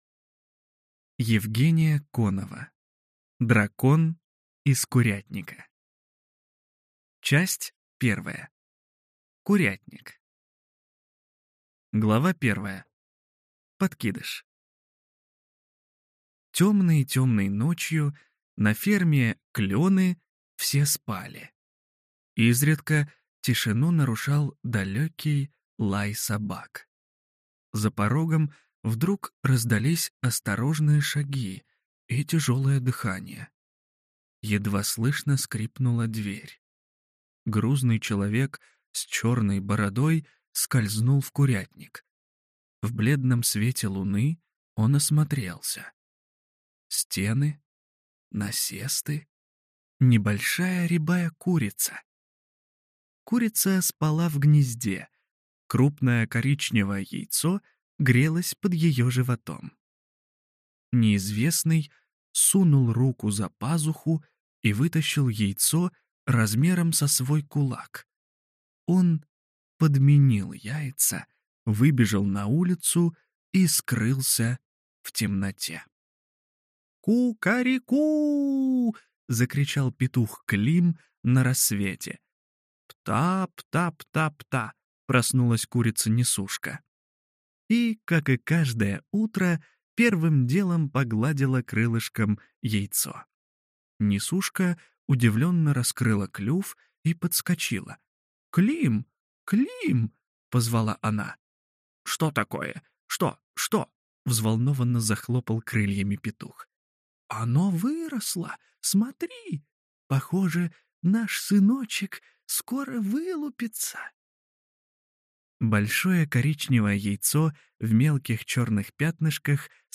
Аудиокнига Дракон из курятника | Библиотека аудиокниг